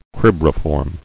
[crib·ri·form]